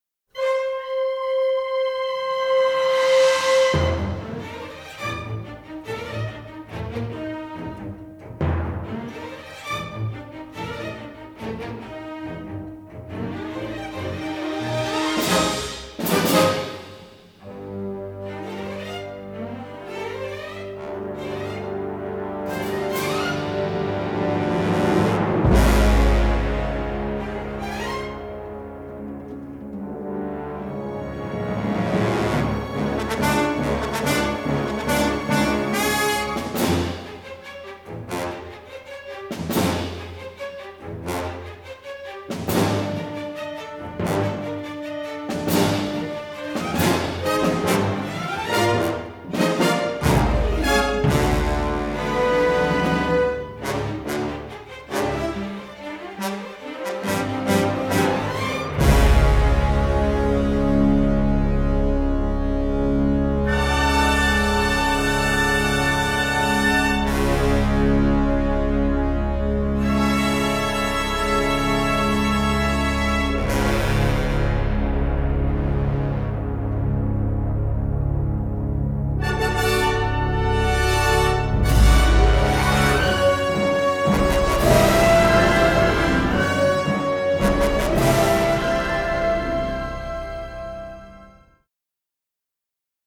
Unusually crisp, punchy recording